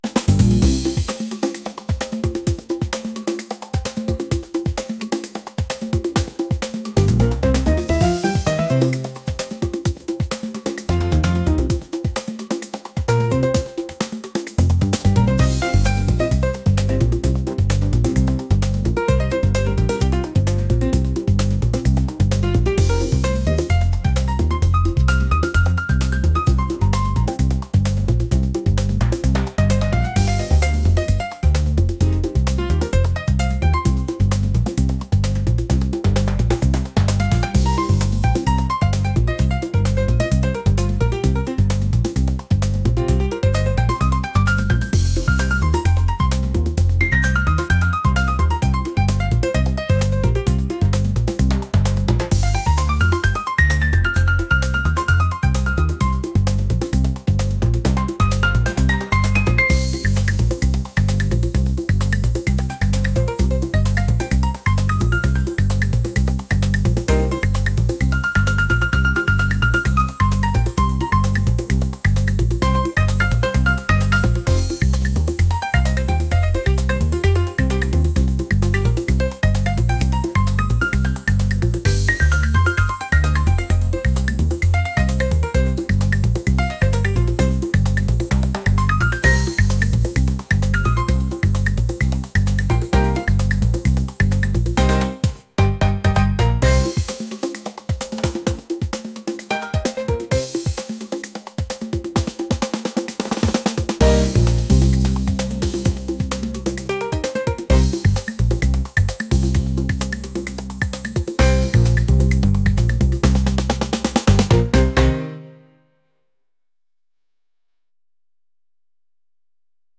jazz | lively | rhythmic